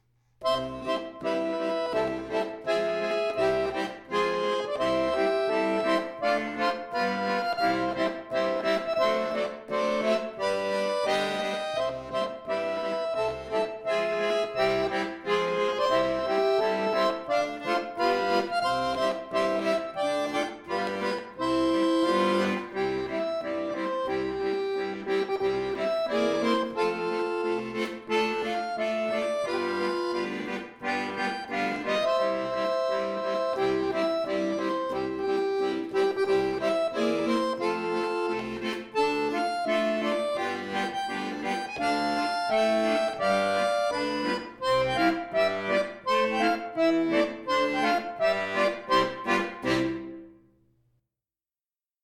relativ leicht spielbar, für Akkordeon solo
Weihnachtslied